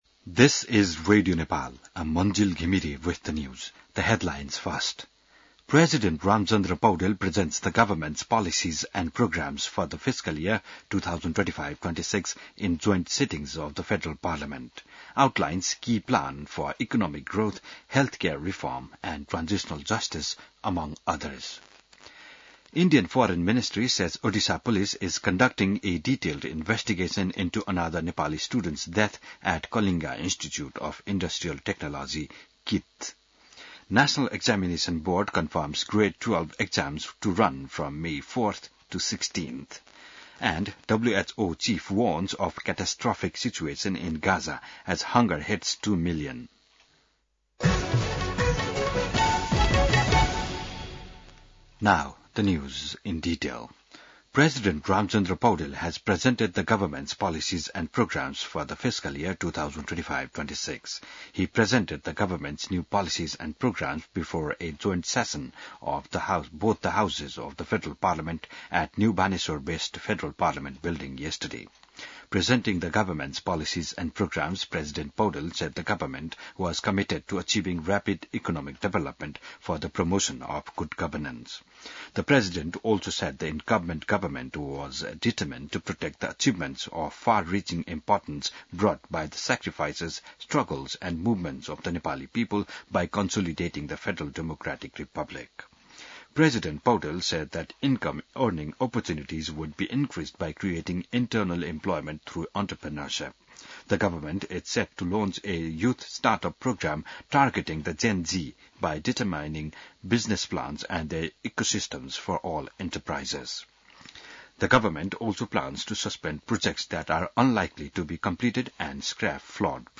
बिहान ८ बजेको अङ्ग्रेजी समाचार : २० वैशाख , २०८२